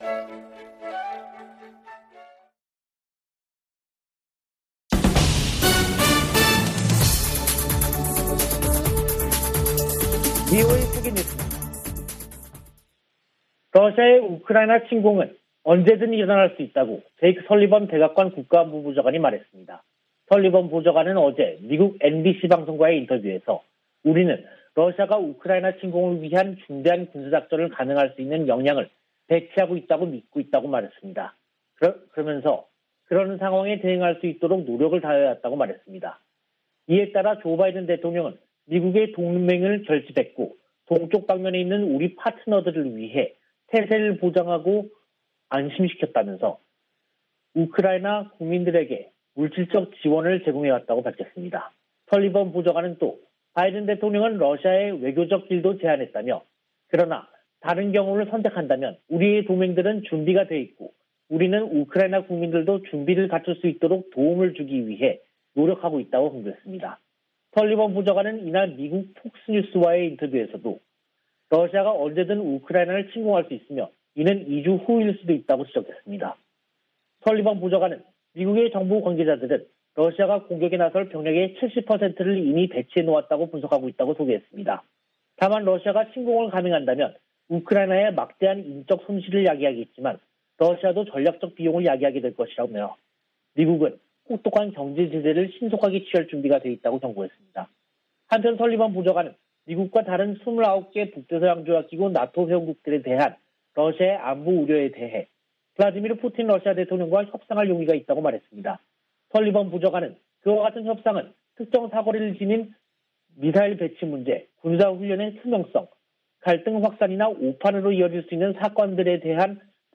VOA 한국어 간판 뉴스 프로그램 '뉴스 투데이', 2022년 2월 7일 3부 방송입니다.